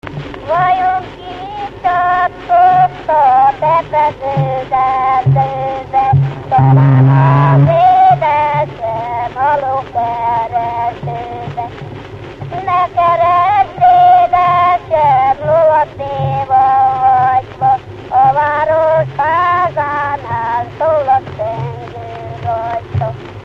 Erdély - Maros-Torda vm. - Deményháza
Gyűjtő: Vikár Béla
Stílus: 7. Régies kisambitusú dallamok
Kadencia: 3 (2) 1 1